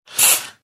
Spray de ambientador del baño (1 toque)
pulverizador
spray
Sonidos: Hogar